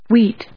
/(h)wíːt(米国英語), wíːt(英国英語)/